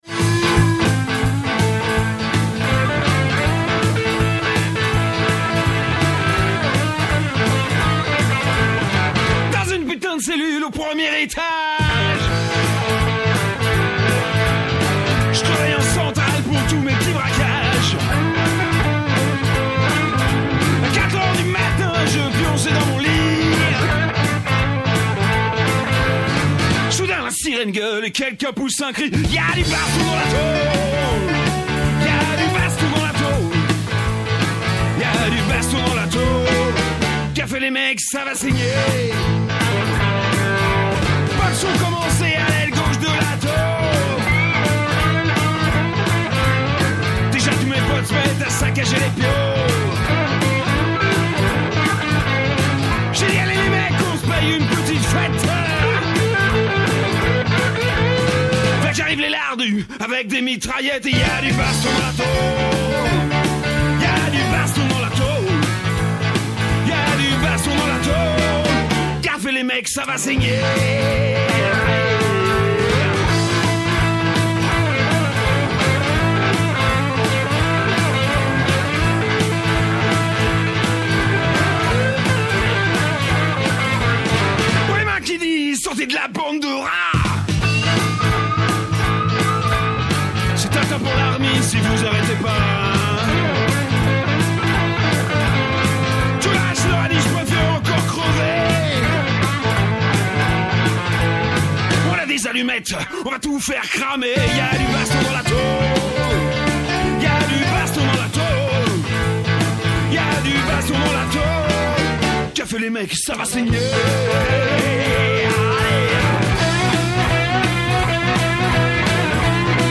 Émission de l’Envolée du vendredi 1er novembre 2024 Dans cette émission : L’Envolée est une émission radio pour en finir avec toutes les prisons.